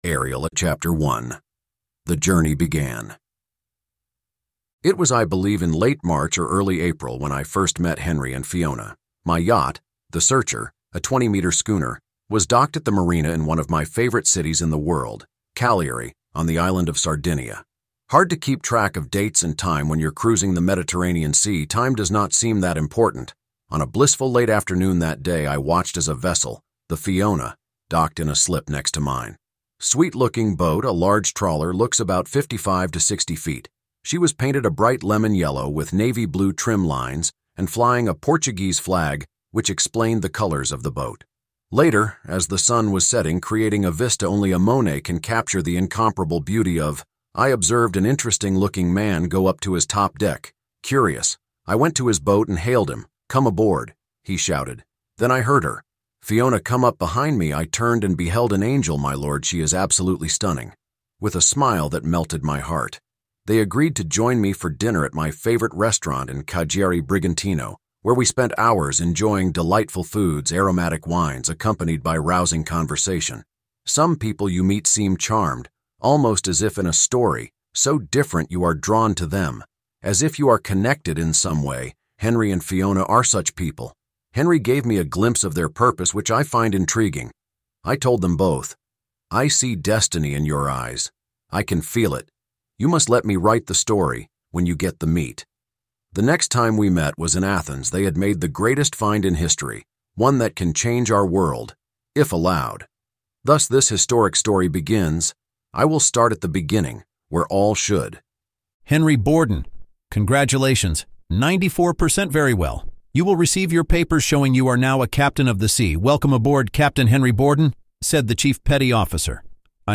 PREVIEW AUDIO BOOK ARIEL, THE CHILD WHO WALKED WITH CHRIST